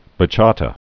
(bə-chätə)